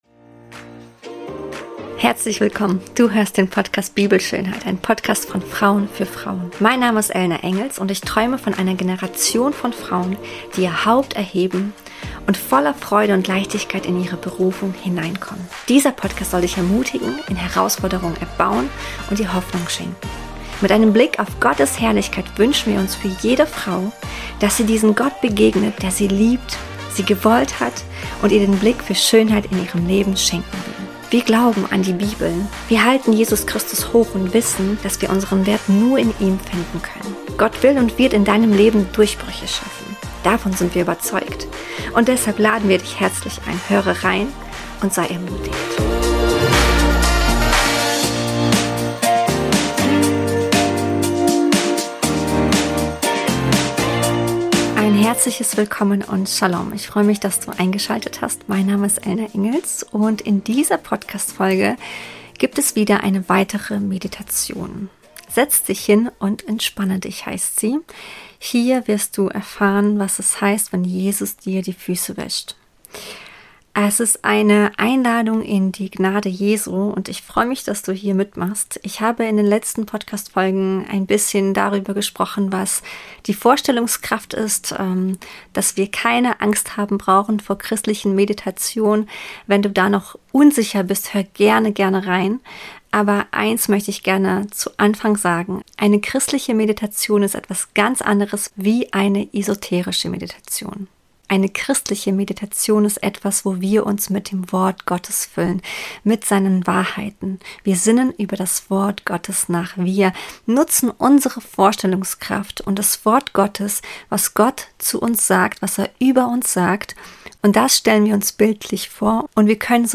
Meditation: Setz dich hin und entspanne dich ~ BIBELSCHÖNHEIT Podcast